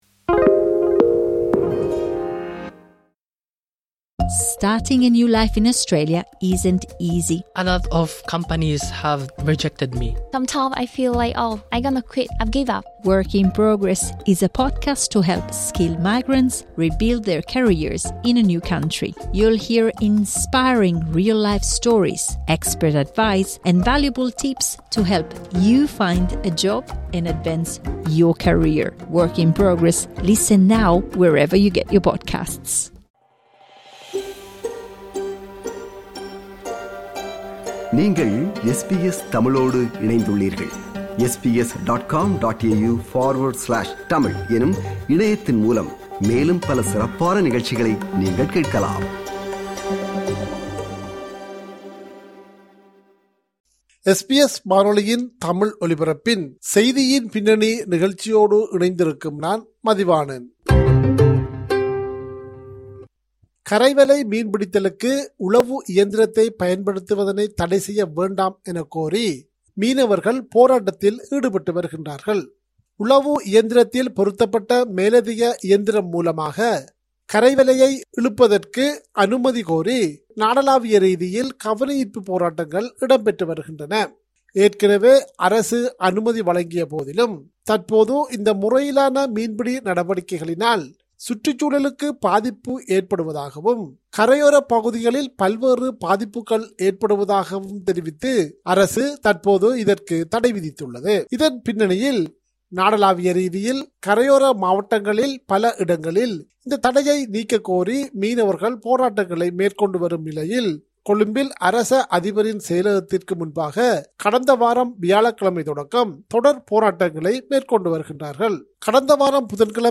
நிகழ்ச்சி ஒன்றை முன்வைக்கிறார் நமது இலங்கைச் செய்தியாளர்